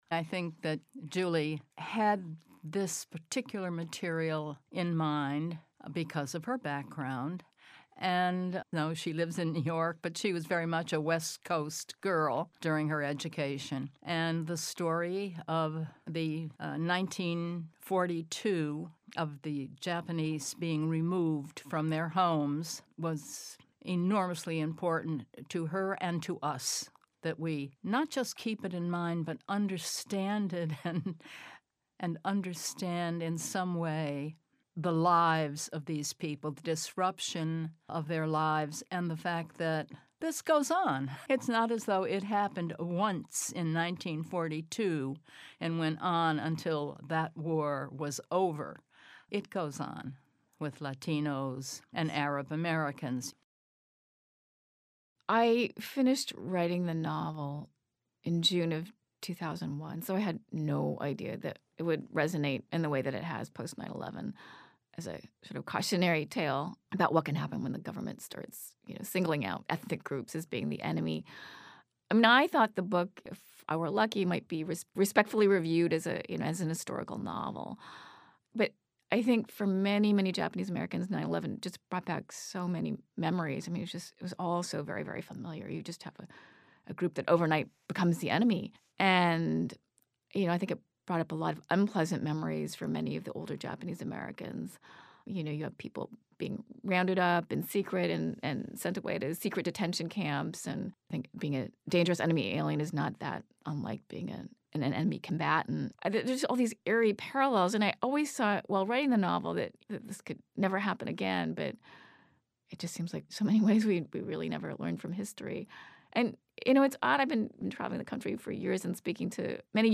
Writer Maureen Howard talks about the treatment of immigrants.